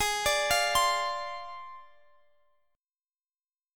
Listen to G#6b5 strummed